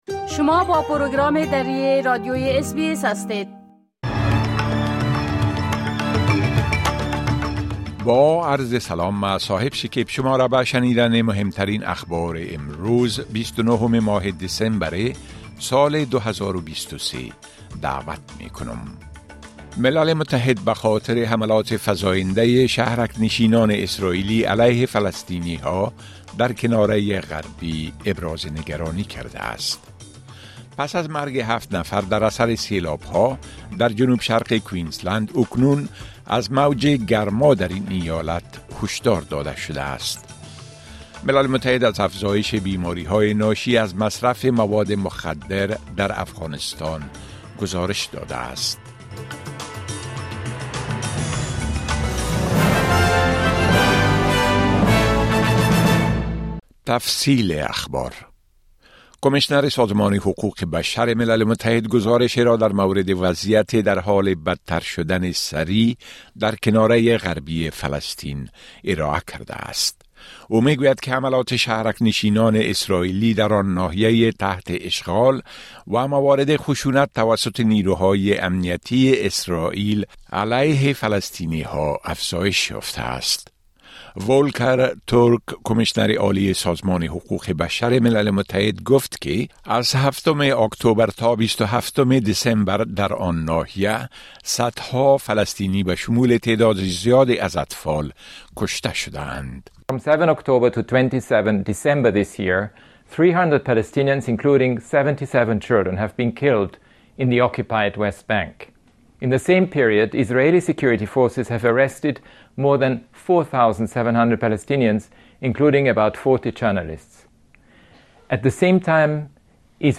گزارش رويدادهاى مهم اخير از برنامۀ درى راديوى اس بى اس